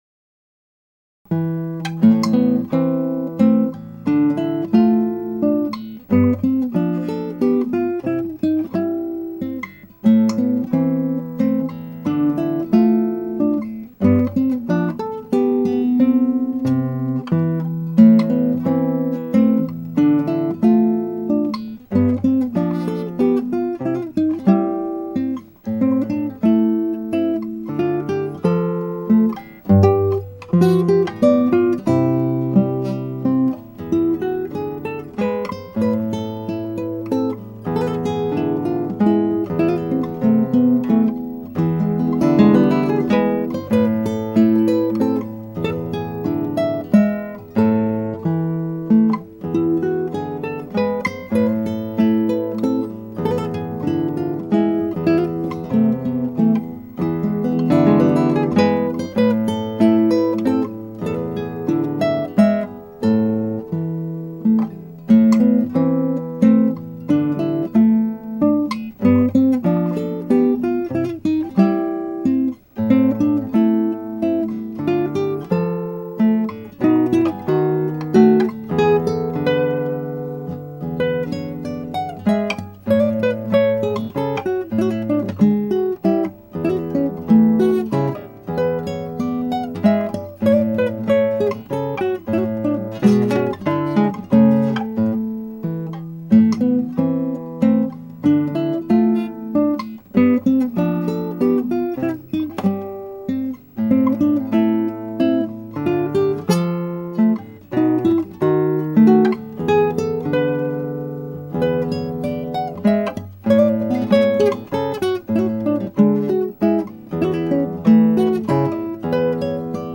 (アマチュアのクラシックギター演奏です [Guitar amatuer play] )
初録より30秒ぐらい短くなり(3分10秒を2分41秒に)テンポを上げました。
そのためテクニックが追いつかず荒っぽい演奏になり音の出ていないところがあるなどあまりよくありません。